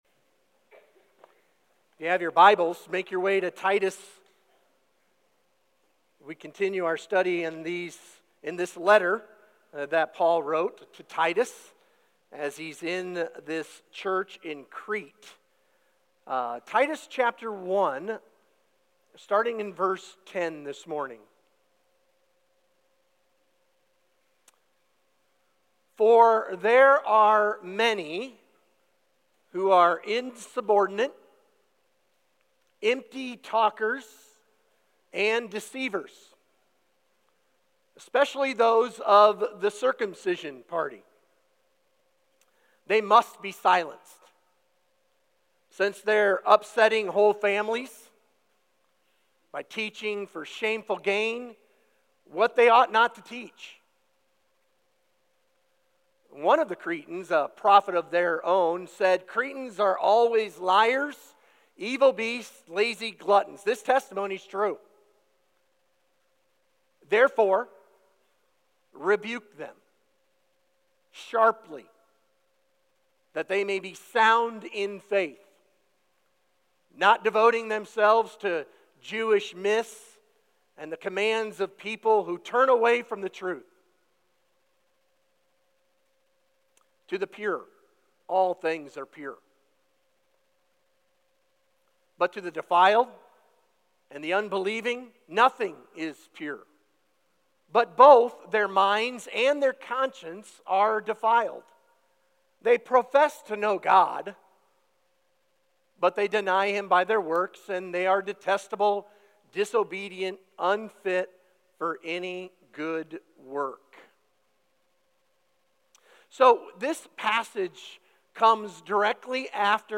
Sermon Questions Read Titus 1:10–16 What to look for in false teachers.